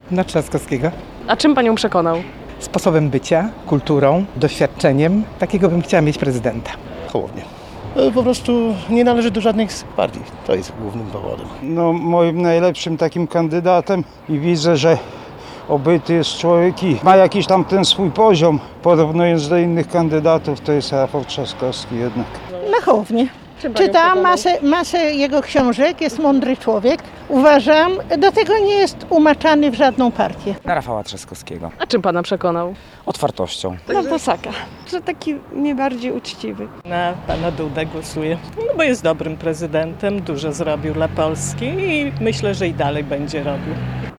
Wczoraj (28.06) byliśmy z mikrofonem Radia 5 pod ełckimi lokalami wyborczymi i pytaliśmy ełczan, na kogo oddali swój głos.
Sonda.mp3